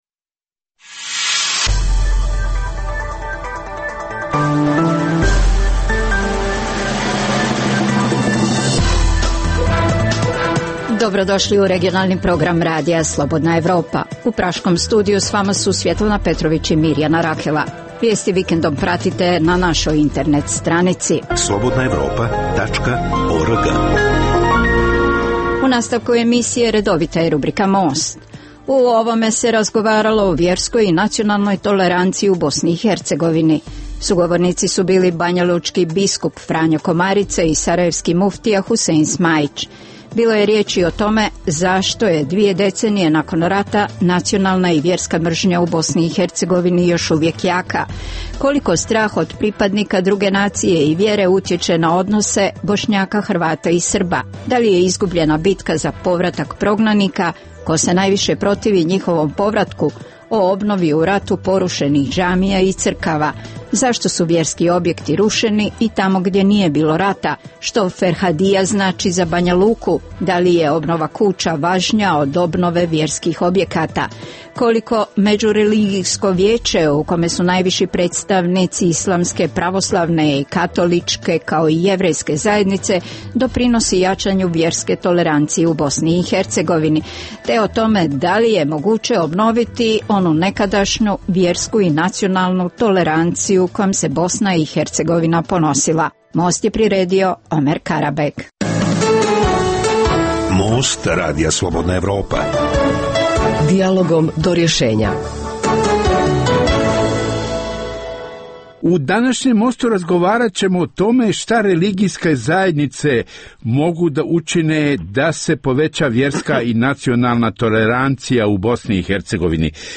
U najnovijem Mostu razgovaralo se o vjerskoj i nacionalnoj toleranciji u Bosni i Hercegovini. Sagovornicu su bili banjalučki biskup Franjo Komarica i sarajevski muftija Husein Smajić.